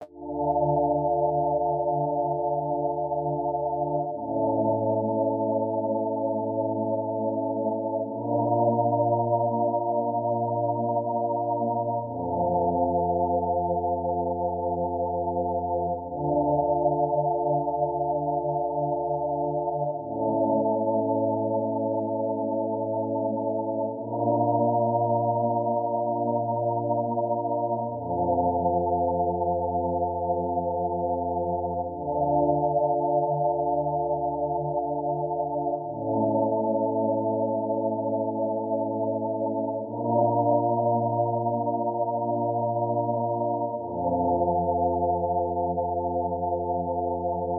🔹 50 Premium Serum Presets crafted for melodic house, cinematic soundscapes, and deep emotional productions.
• Layered & Textured Sounds for that big cinematic feel
Preset Preview
RearView-Lights-0015-Instrument-PD-Dandelight.wav